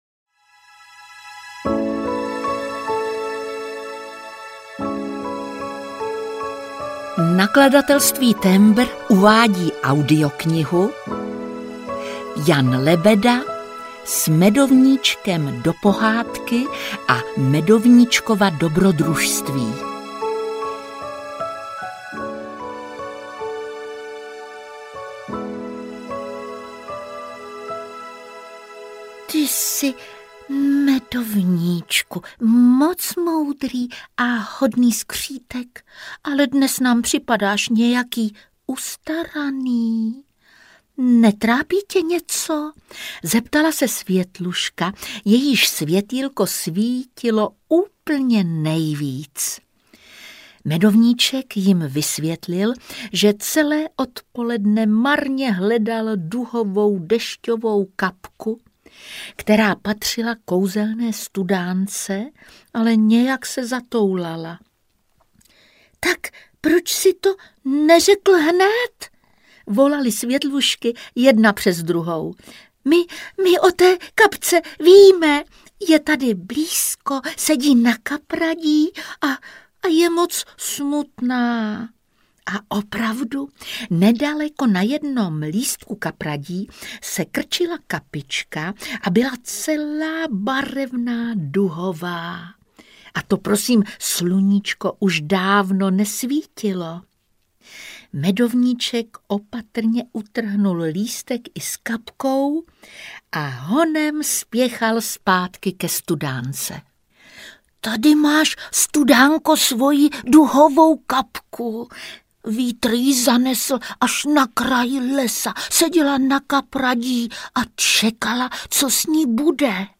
S Medovníčkem do pohádky a Medovníčkova dobrodružství audiokniha
Ukázka z knihy
• InterpretJitka Molavcová